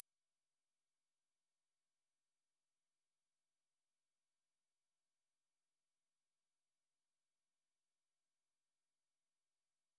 Звуки ультразвука
14400 Гц — этот ультразвук услышат только люди до сорока